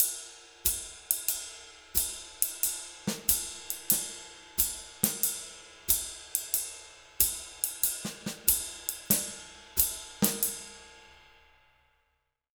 92SWING 02-L.wav